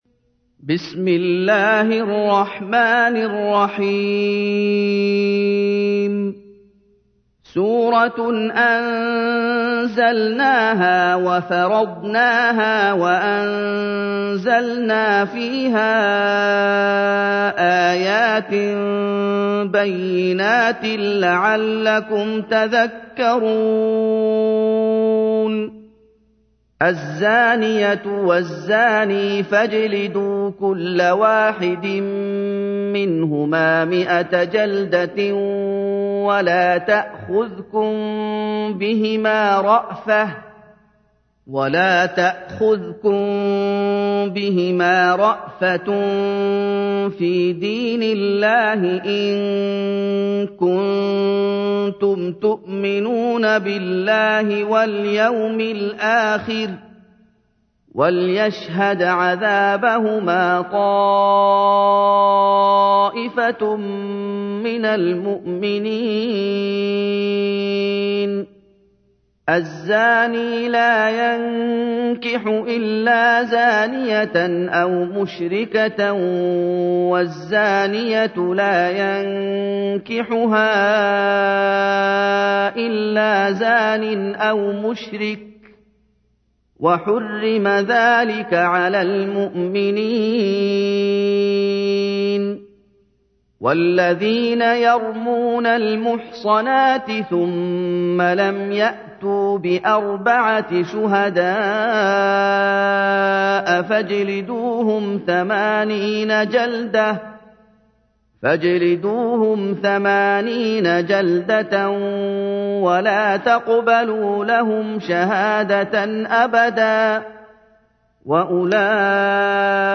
تحميل : 24. سورة النور / القارئ محمد أيوب / القرآن الكريم / موقع يا حسين